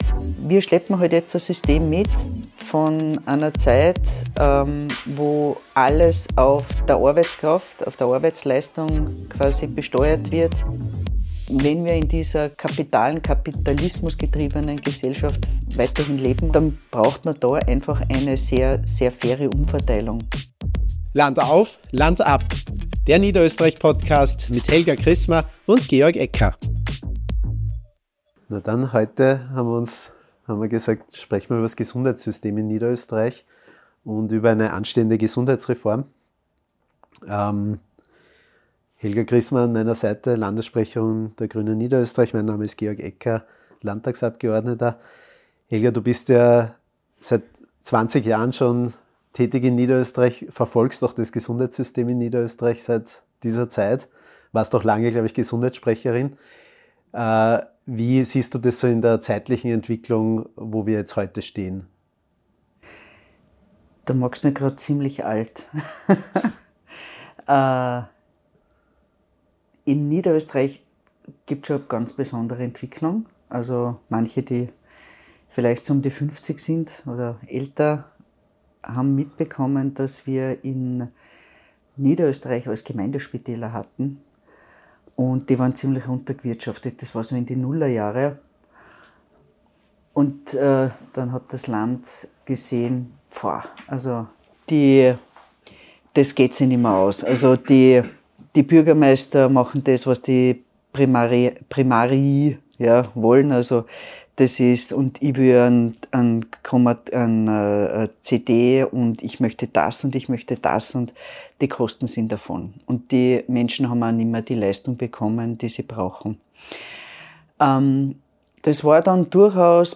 Landessprecherin und Klubobfrau Helga Krismer spricht mit Landtagsabgeordnetem Georg Ecker über die Hintergründe des niederösterreichischen Gesundheitswesens, was aus ihrer Sicht wirkliche Verbesserungen wären und über die Vision eines guten Systems, das vor allem auf Verteilungsgerechtigkeit aufbaut und die Wünsche der Patient:innen voran stellt.